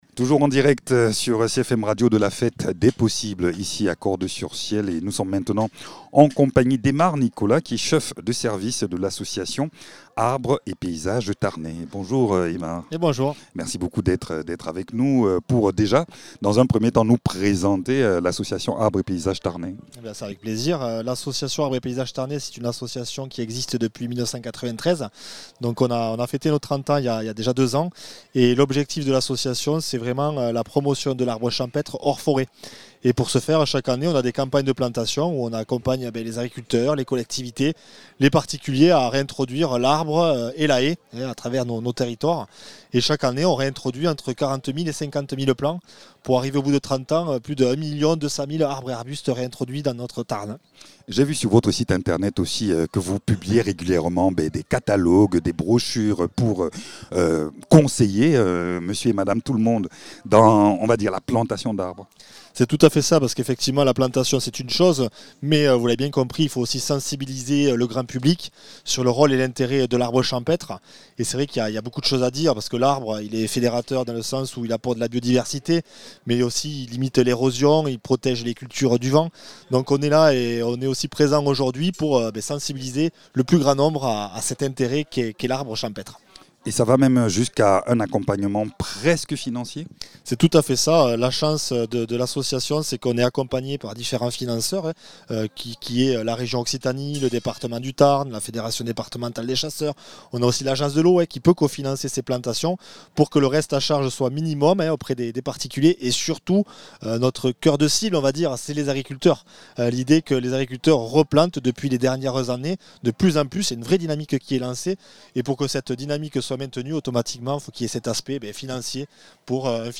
Ils plantent, conseillent, préservent et sensibilisent : Arbres et Paysages Tarnais œuvre depuis des années pour redonner place aux haies, arbres ruraux et paysages vivants dans le département. Dans cette interview, l’association revient sur ses missions, ses actions de terrain et les enjeux écologiques qui traversent aujourd’hui nos campagnes.